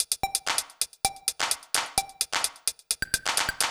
TEC Beat - Mix 9.wav